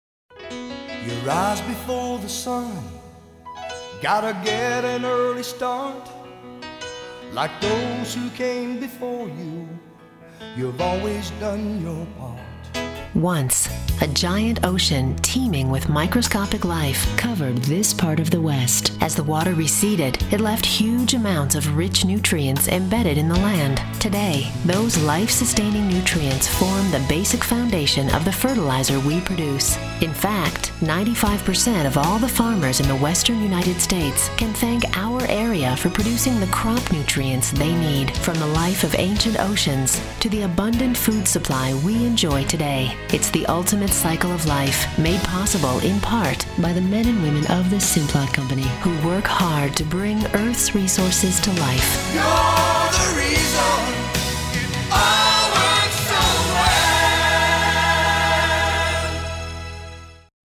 1 minute radio spot